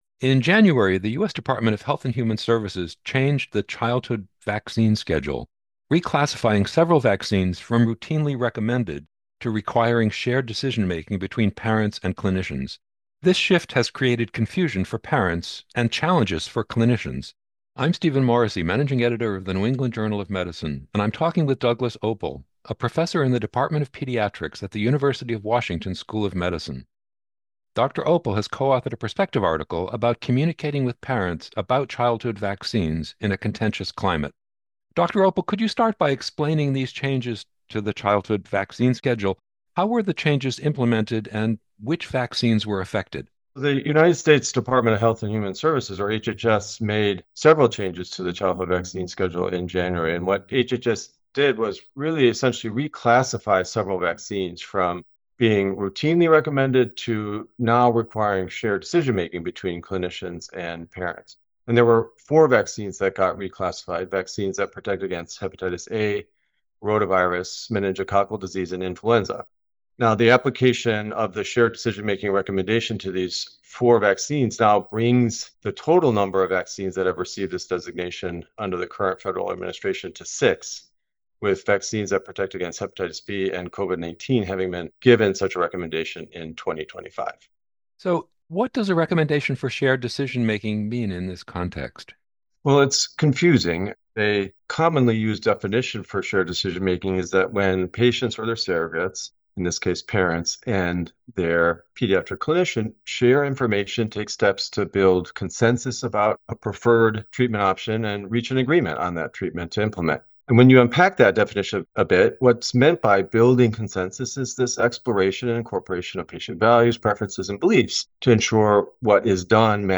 NEJM Interview